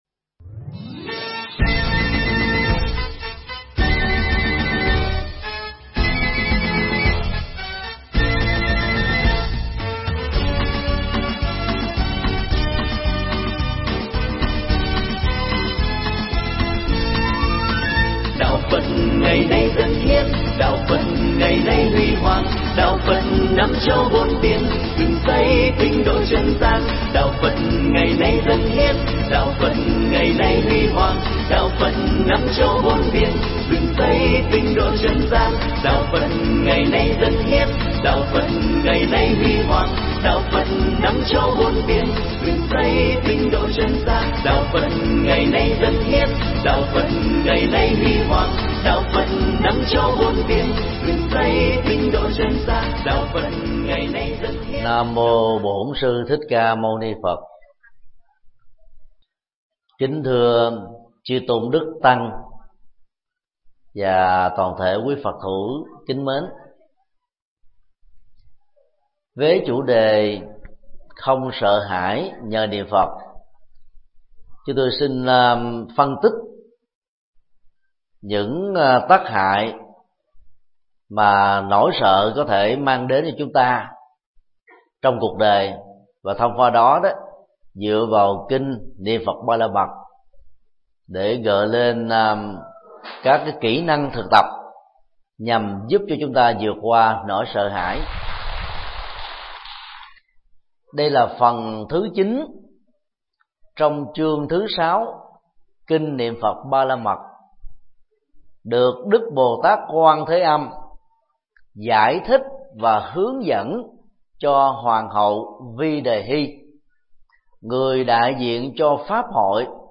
Mp3 Bài giảng Kinh Niệm Phật Ba La Mật 18: Không sợ hãi
giảng tại thiền viện Bồ Đề, Đà Nẵng